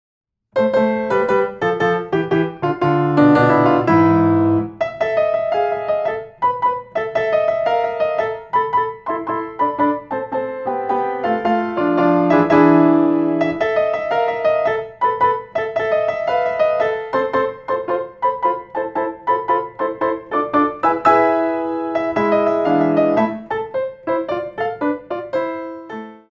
4 bar intro 6/8